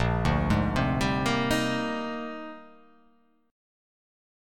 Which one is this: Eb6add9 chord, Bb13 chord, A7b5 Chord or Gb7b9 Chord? Bb13 chord